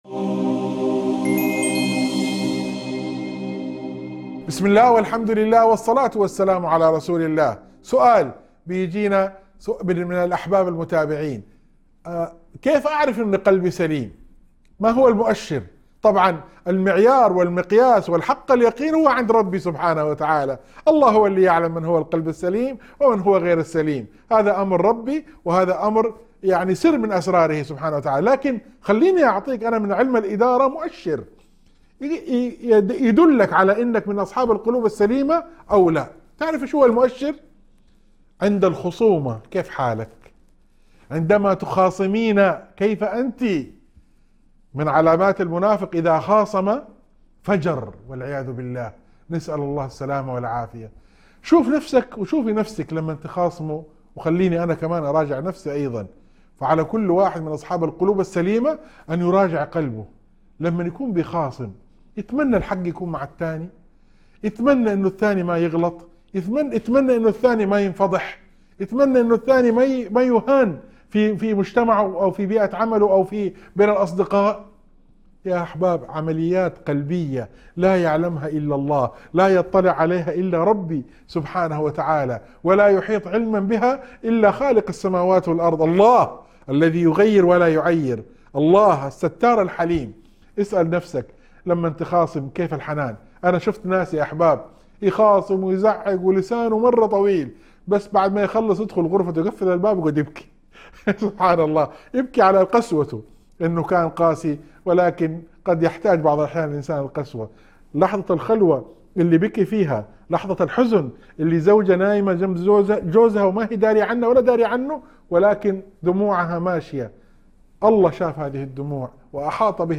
موعظة تركز على كيفية معرفة سلامة القلب من خلال اختبار الخصومة ومراجعة النفس. تشرح أن المؤشر الحقيقي هو سلوك الإنسان عند الخصام، داعية إلى طلب القلب السليم والستر من الله.